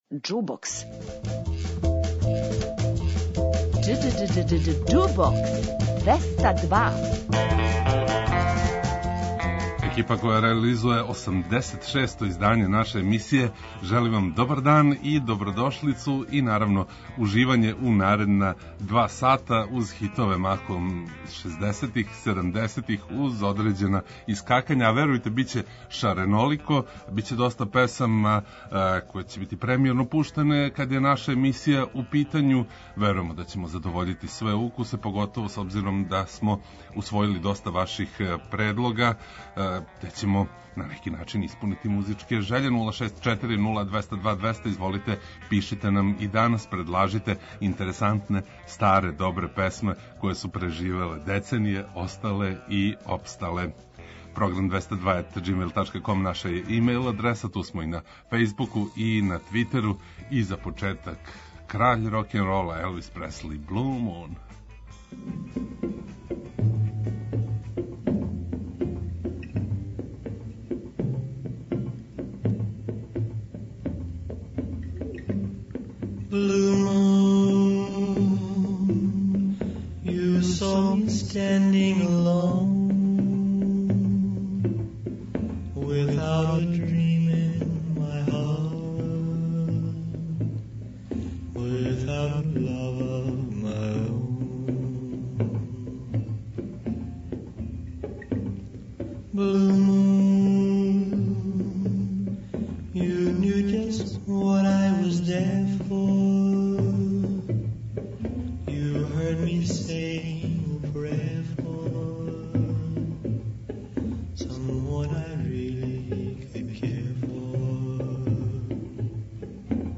Готово све су страни хитови, само је једна нумера са „наших терена”. Усвојили смо, као и прошле суботе, велики број ваших предлога.